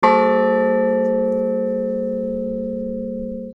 Unfinished Church Bells
35inch Stuckstede 1894